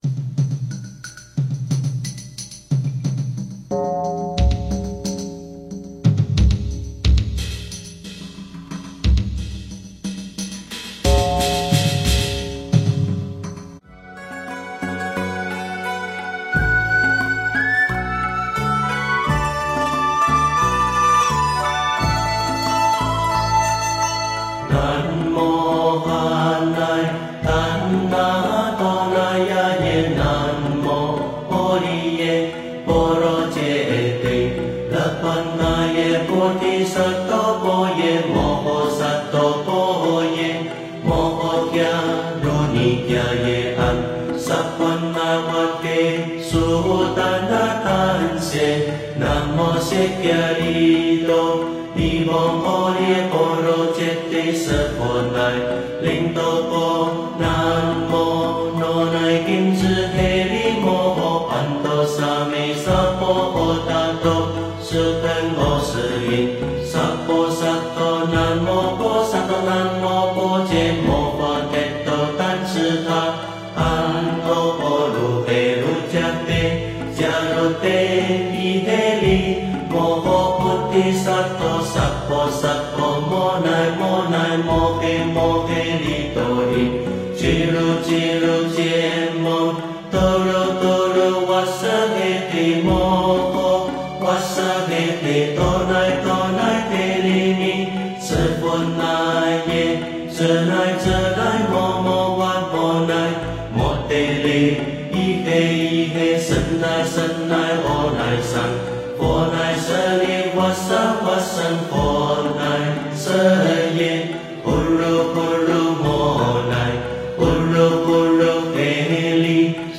心陀罗尼经大悲神咒 诵经 心陀罗尼经大悲神咒--佛教音乐 点我： 标签: 佛音 诵经 佛教音乐 返回列表 上一篇： 劝世佛歌 下一篇： 文殊菩萨 相关文章 解深密经-1（念诵） 解深密经-1（念诵）--未知...